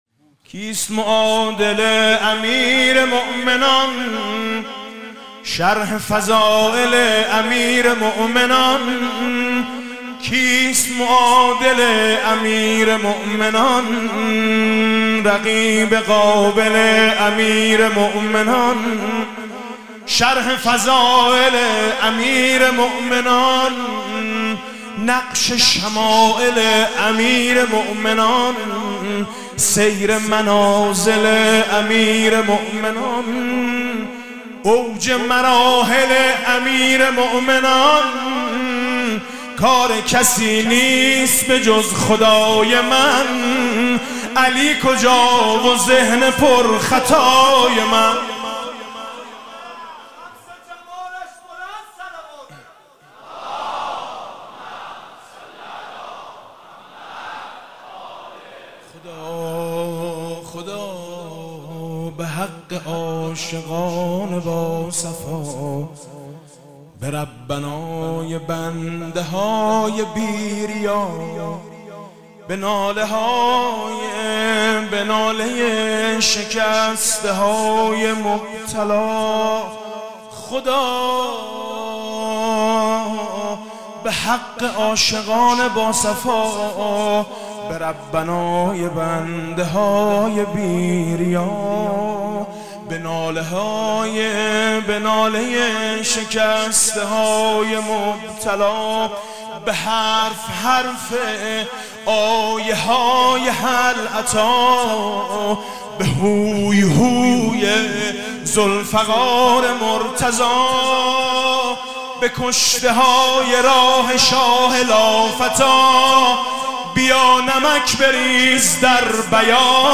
مدح: کسیت معادل امیرمؤمنان؟ شرح فضائل امیرمؤمنان؟